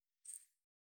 355塩を振る,調味料,カシャカシャ,サラサラ,パラパラ,ジャラジャラ,サッサッ,
効果音厨房/台所/レストラン/kitchen